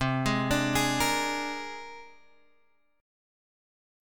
C 7th Suspended 2nd Sharp 5th